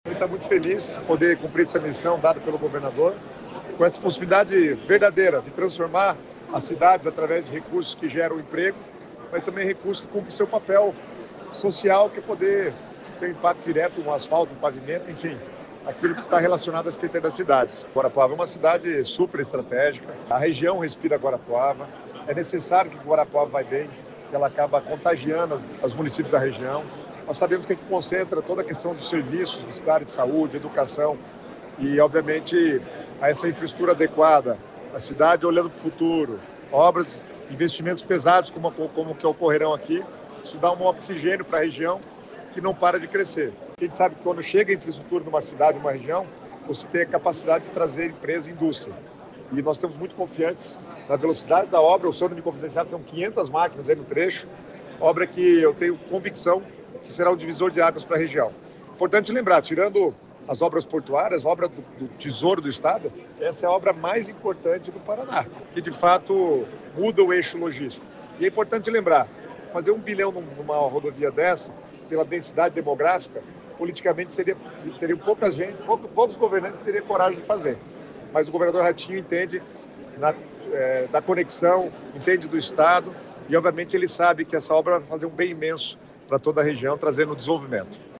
Sonora do secretário das Cidades, Guto Silva, sobre o investimento de R$ 202,7 milhões para os municípios da região de Guarapuava